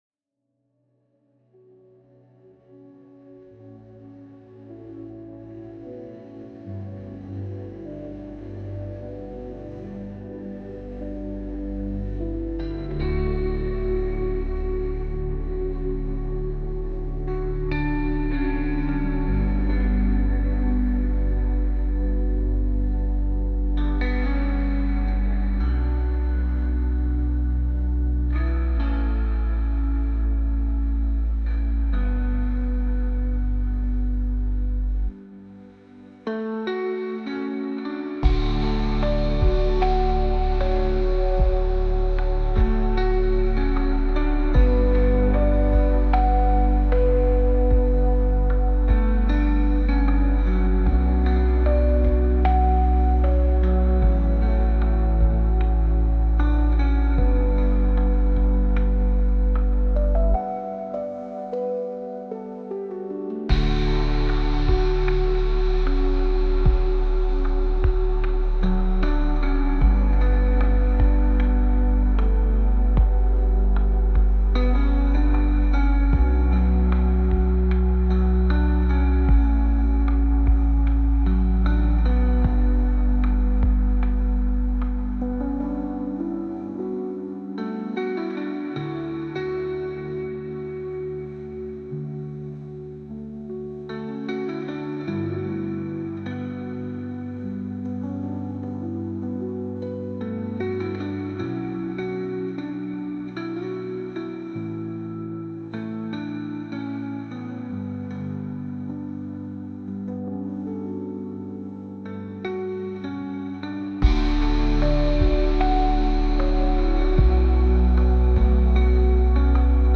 گیتار الکترونیک
موسیقی بی کلام آرامبخش نظرات دیدگاهتان را بنویسید!